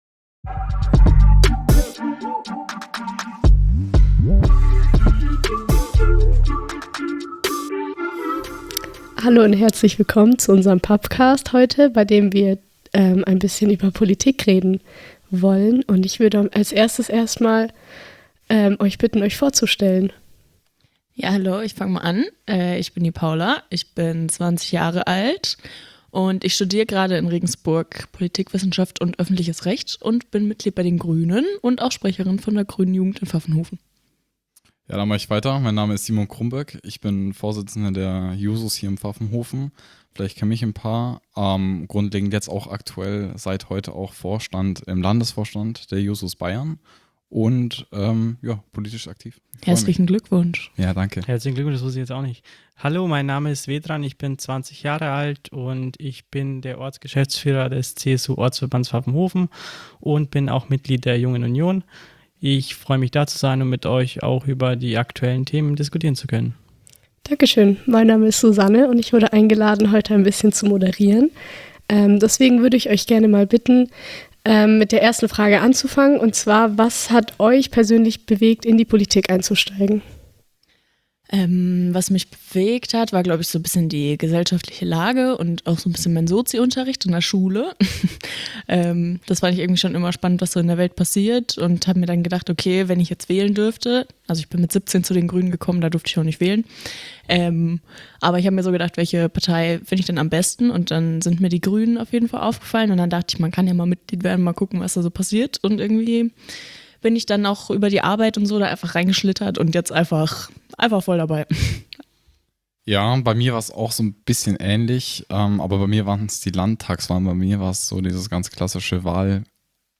In Folge 11 reden und diskutieren Vertreter der Jugendorganisationen der Union, SPD und der Grünen über ihr Wahlprogramm.